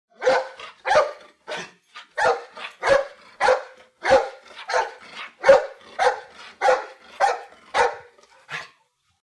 Звук собачьего гавканья